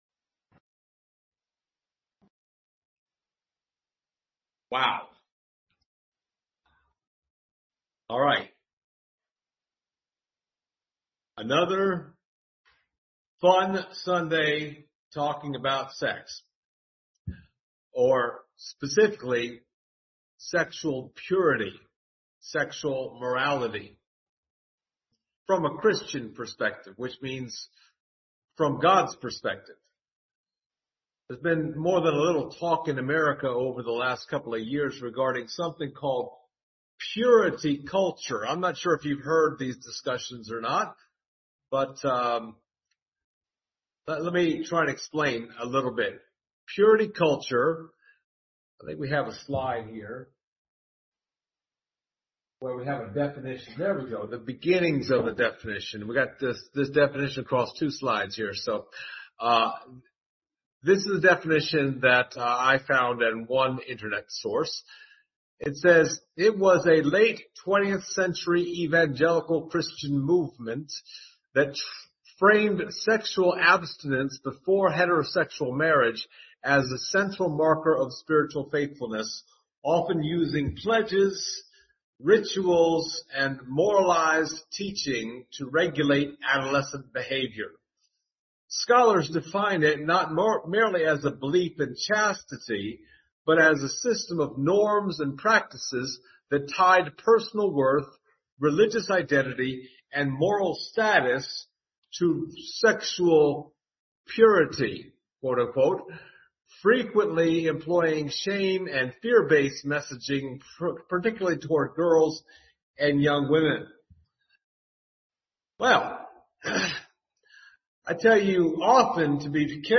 Some people might even say this sermon series is a part of purity culture.
1 Thessalonians 4:3-7 Service Type: Sunday Morning All right!